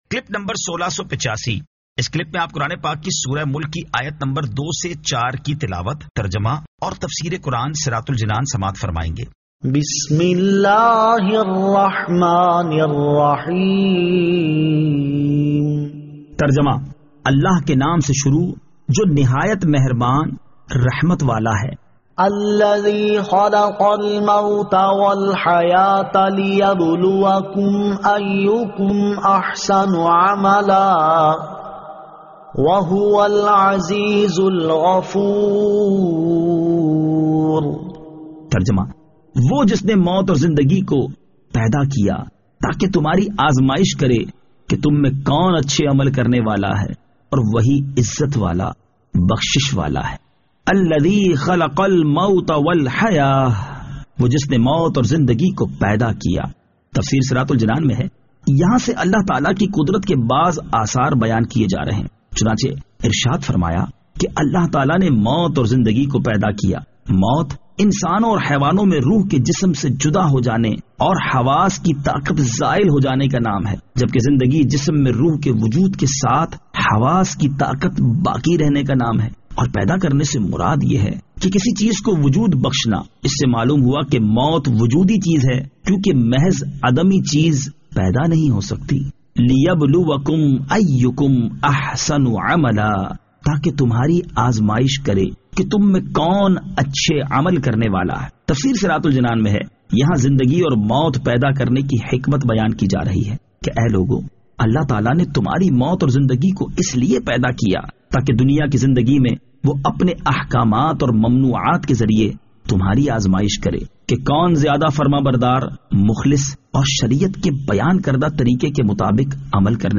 Surah Al-Mulk 02 To 04 Tilawat , Tarjama , Tafseer